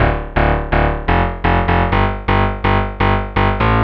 cch_bass_eighter_125_Am.wav